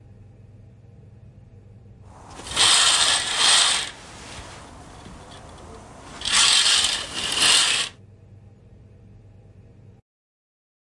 窗帘
描述：打开和关闭金属栏杆上的窗帘。